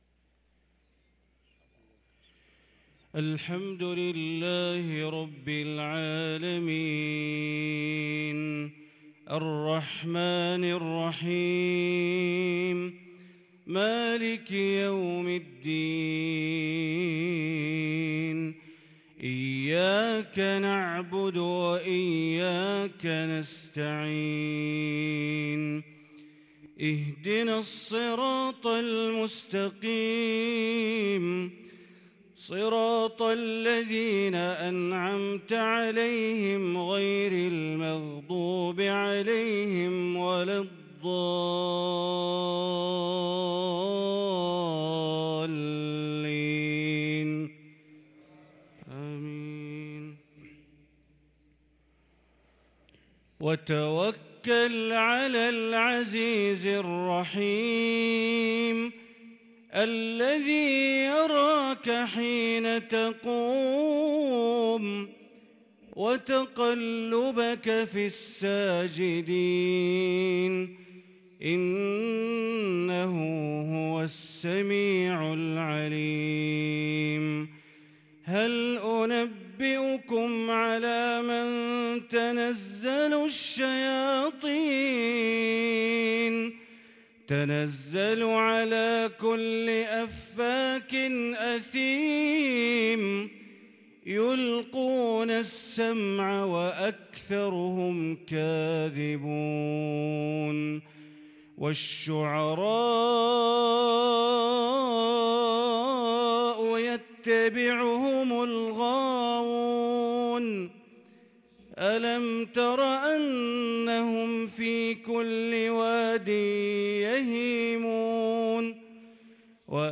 صلاة العشاء للقارئ بندر بليلة 4 جمادي الأول 1444 هـ
تِلَاوَات الْحَرَمَيْن .